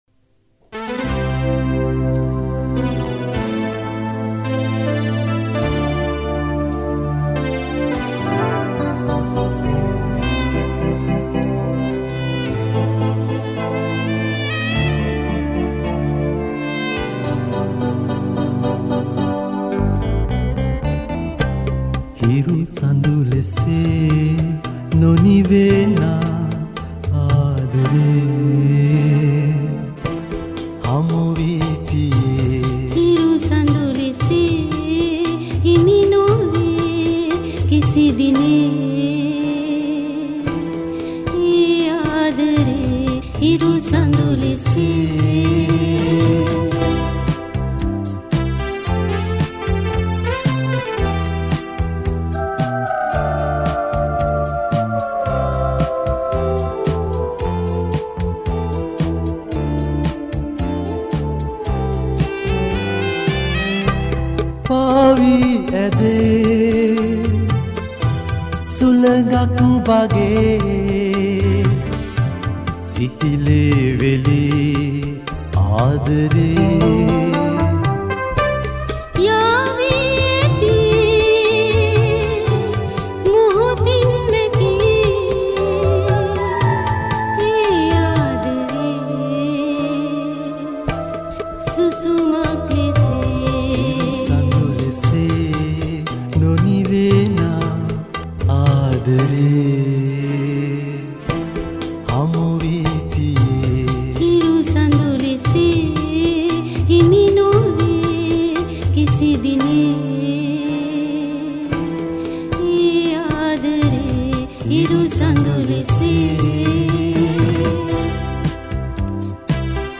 All these songs were recorded (or remastered) in Australia.